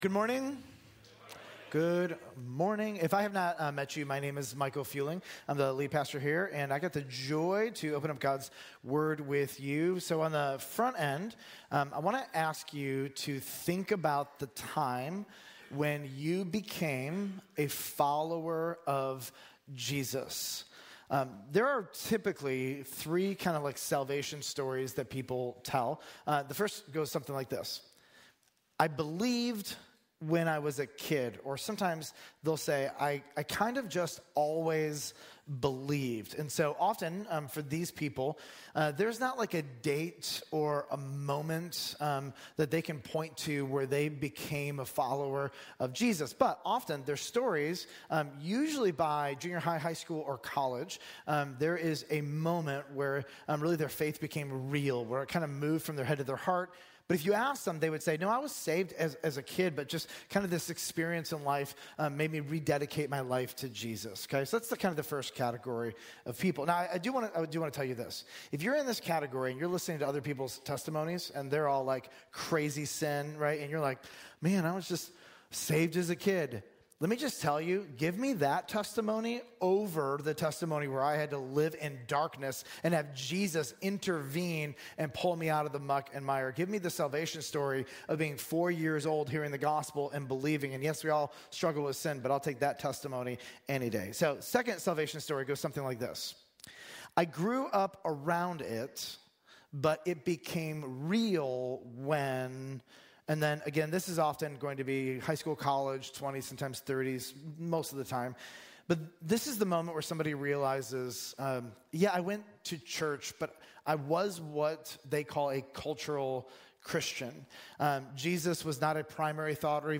Sermons Emmaus